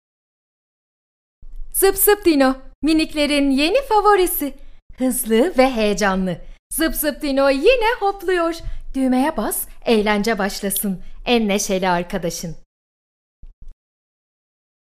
Radio & TV Commercial Voice Overs Talent, Artists & Actors
Yng Adult (18-29) | Adult (30-50)